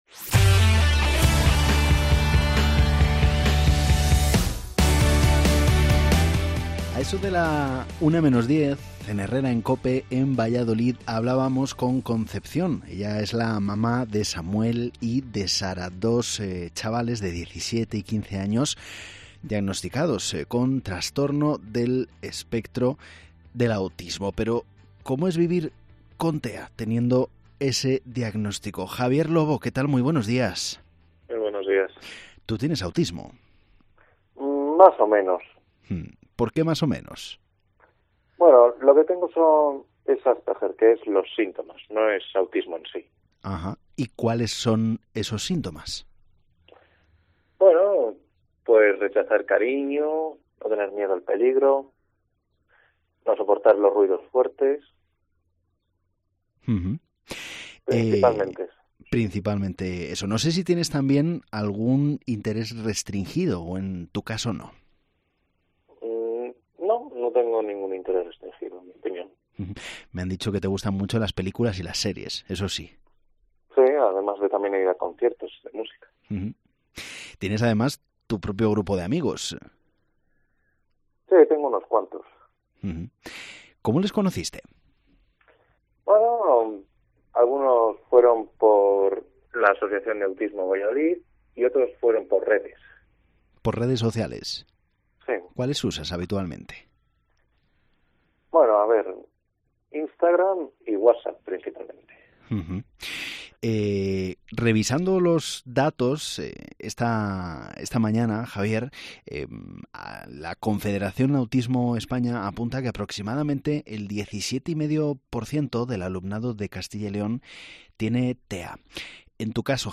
COPE Valladolid te explica el TEA a través del testimonio de un joven con altas capacidades y una madre con dos hijos